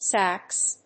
発音記号
• / sæks(米国英語)